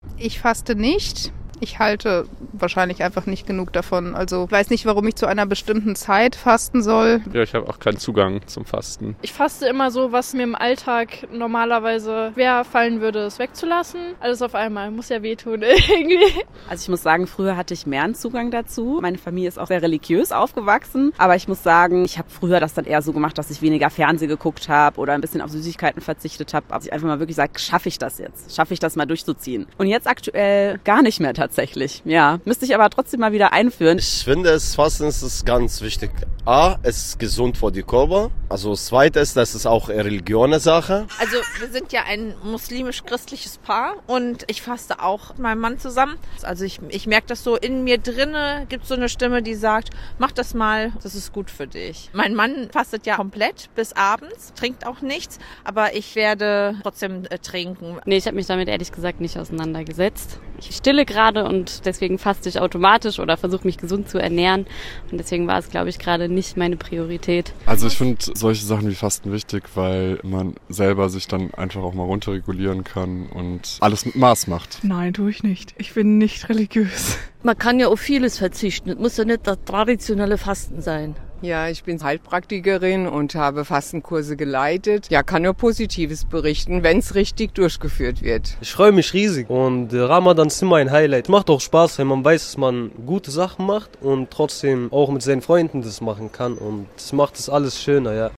Umfrage zum Fasten in Mainz